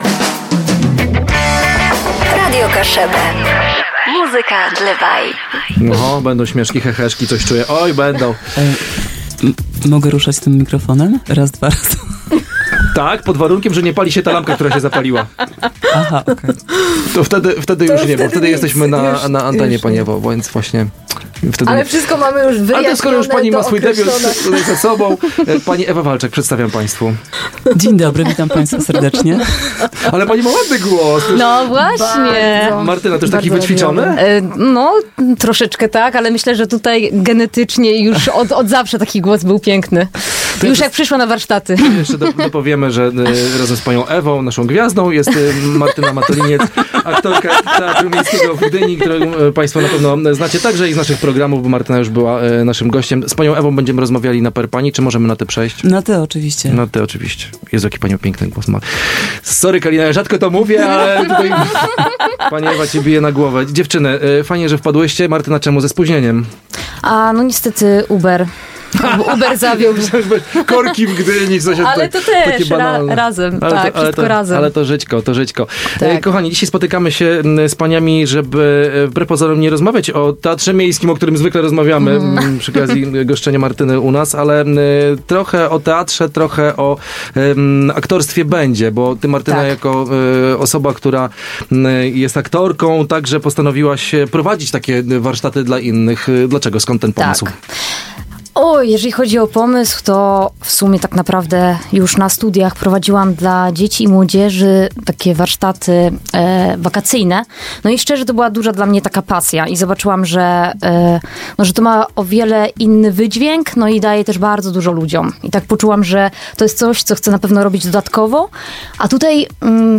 Rozmowa dotyczyła nietypowej inicjatywy, która łączy pasjonatów teatru z różnych środowisk, oferując im możliwość rozwoju aktorskiego i osobistego oraz realizacji na scenie.